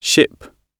ship-gb.mp3